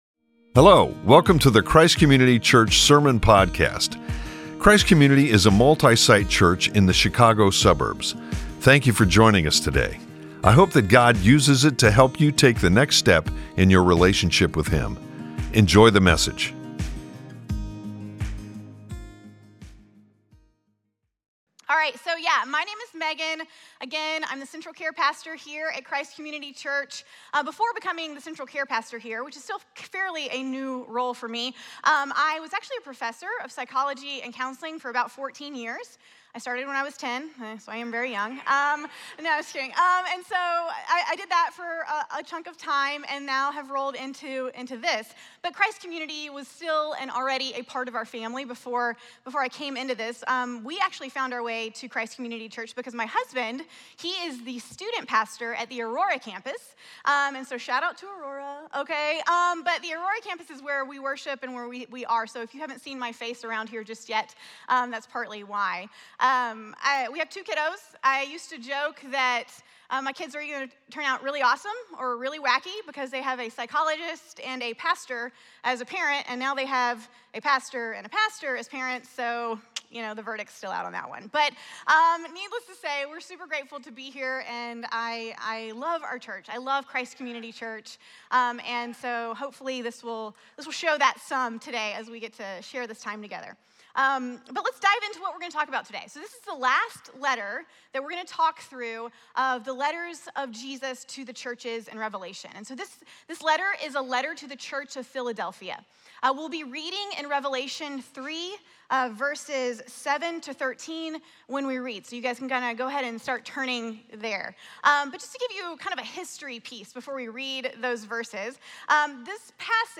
4.13.25-Sermon-audio.mp3